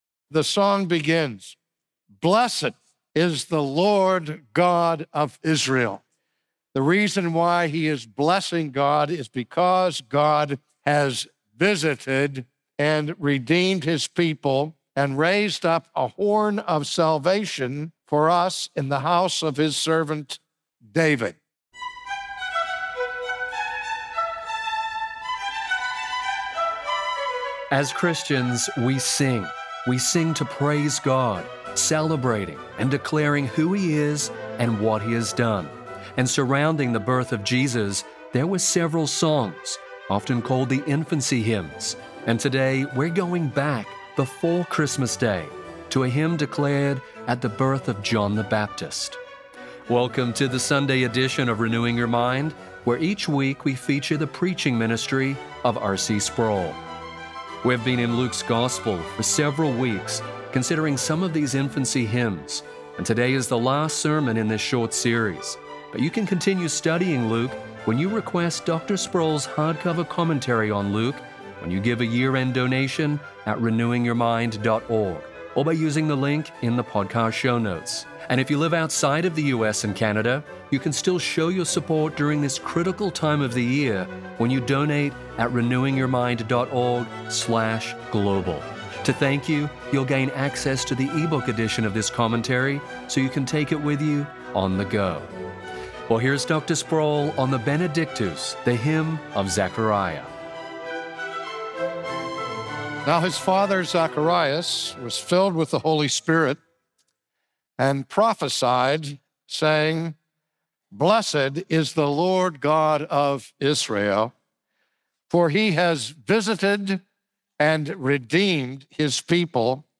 When John the Baptist was born, his father Zacharias praised God in song. From his sermon series in the gospel of Luke, today R.C. Sproul examines this song, the Benedictus, to consider what the birth of the forerunner to Jesus means for God's unfolding plan of redemption.